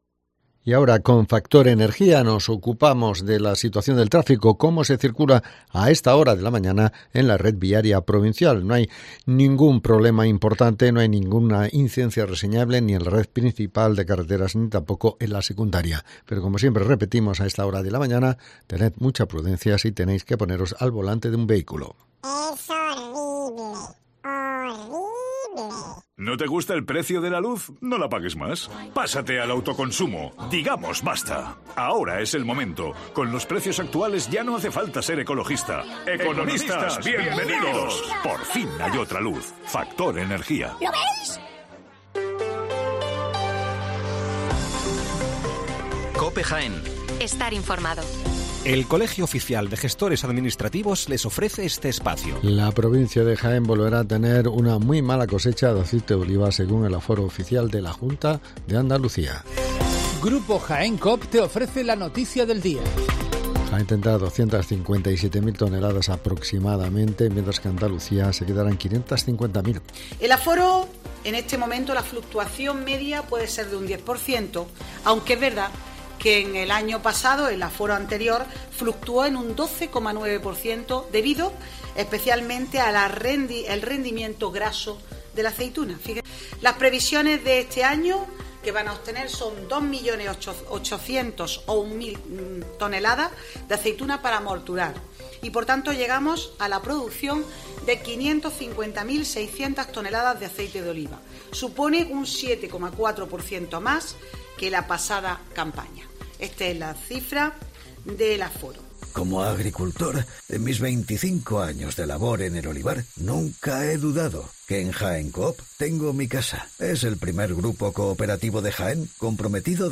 Las noticias locales